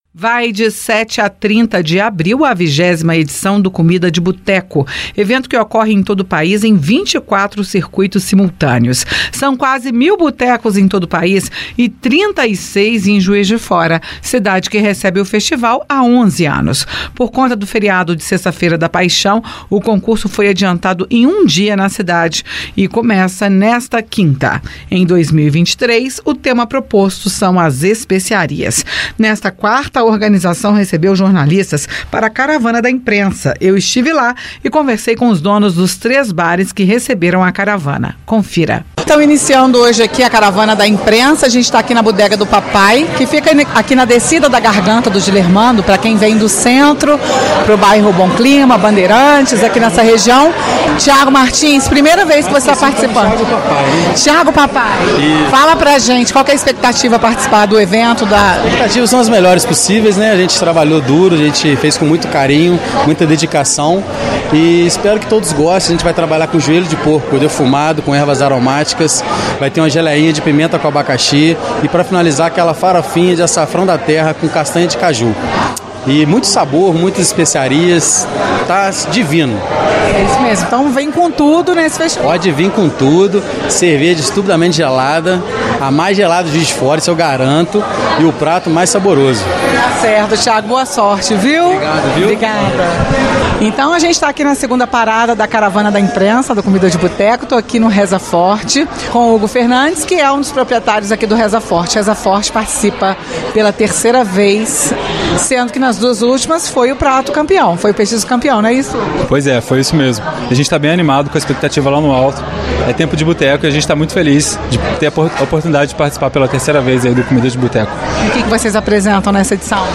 conversou com os donos dos três bares que receberam a caravana